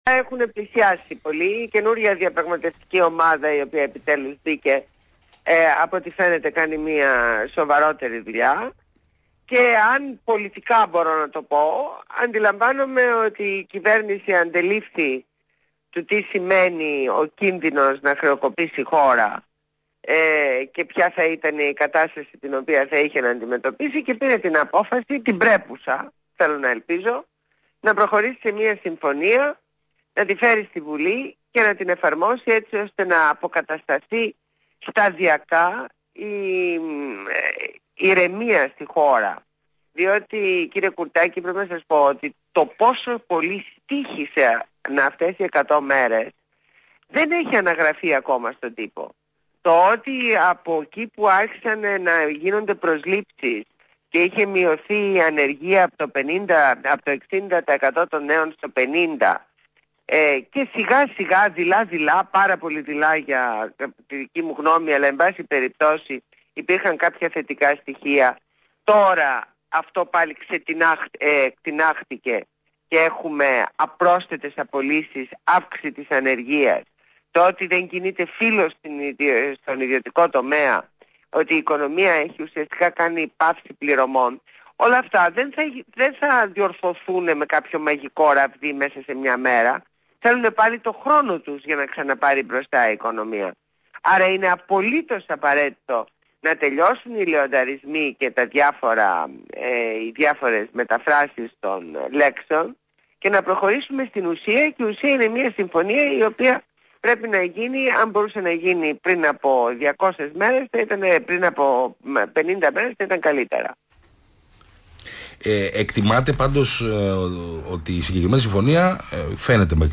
Συνέντευξη στο ραδιόφωνο Παραπολιτικά FM 90,1